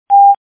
Piepton.mp3